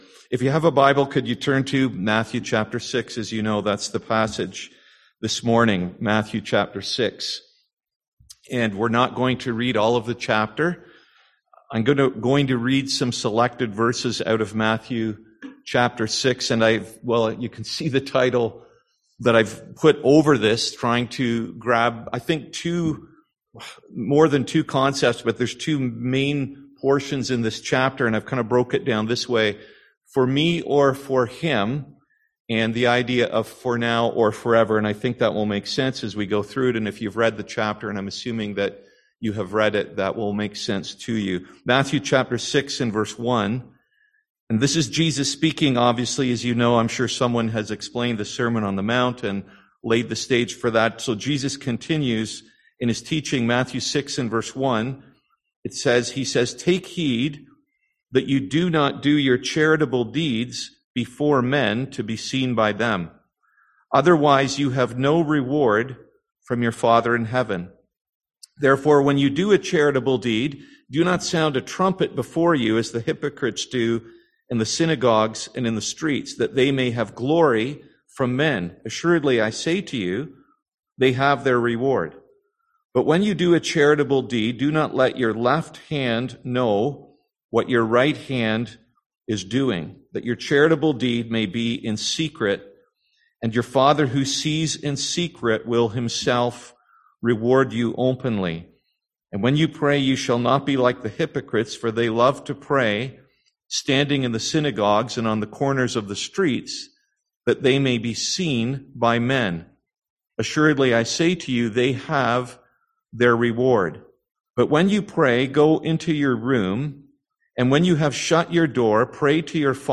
Passage: Matthew 6 Service Type: Sunday AM